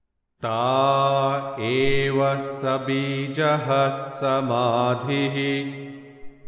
Yoga Sutra 1.46 | Tā eva sa-bījaḥ samādhiḥ | Chant Sutra 1.46